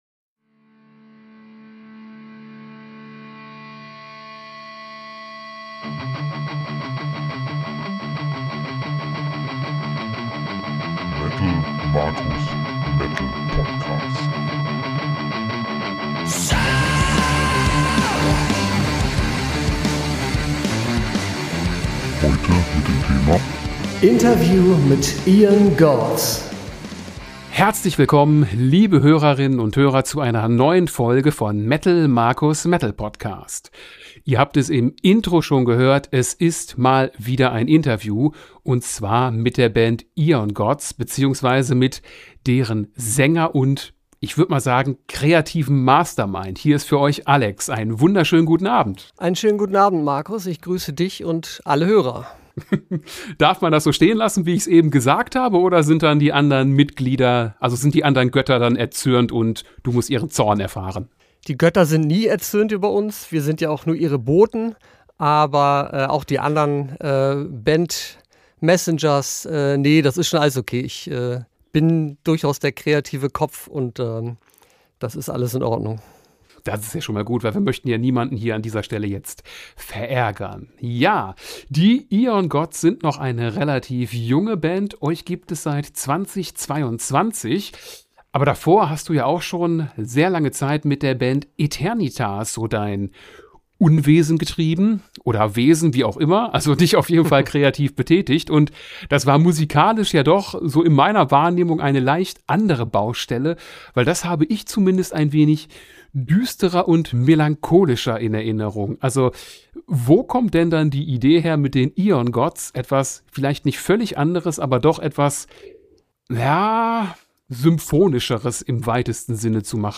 Interview mit Aeon Gods